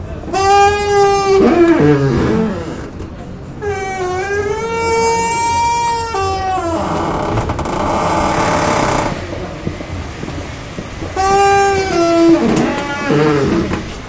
描述：在城市圆环项目的框架做的一个商店的压缩门。
从Escola Basica Gualtar（葡萄牙）和它的周围的领域录音，由8岁的学生做。